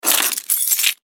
Звук автомобильных ключей в наших руках